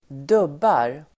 Uttal: [²d'ub:ar]